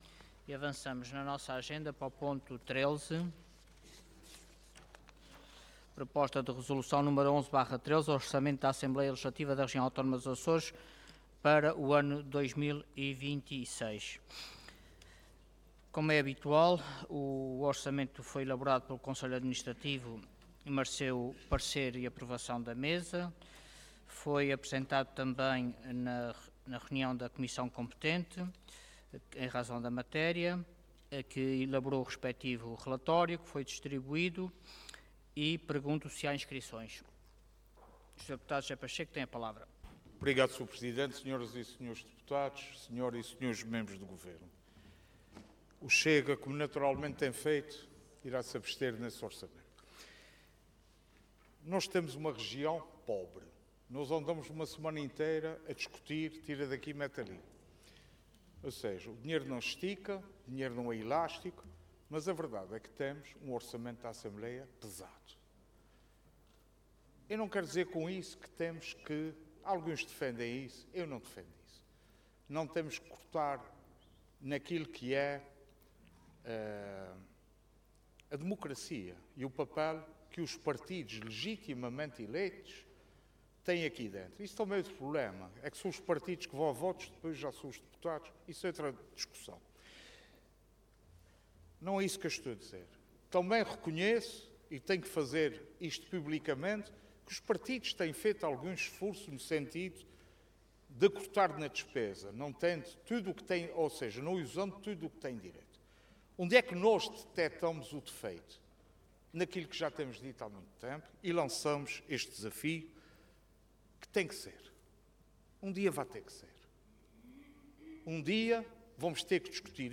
Intervenção
Orador Luís Garcia Cargo Presidente da Assembleia Regional